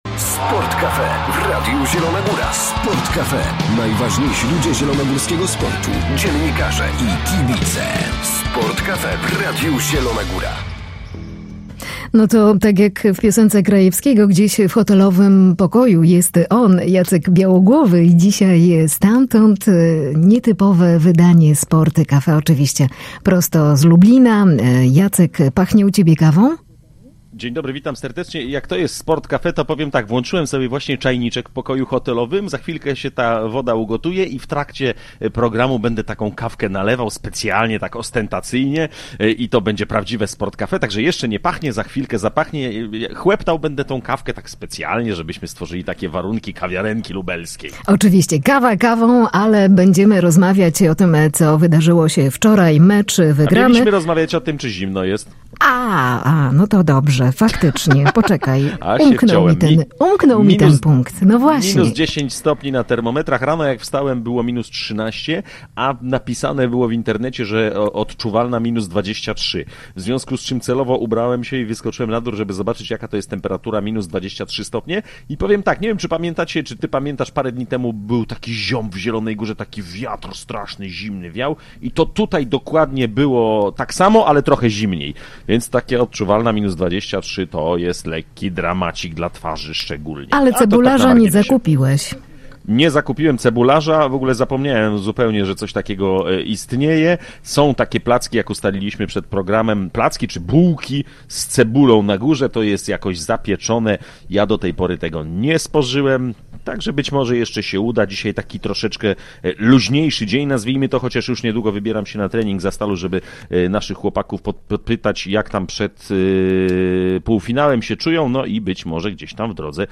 Sport cafe: RZG nadaje z Pucharu Polski w Lublinie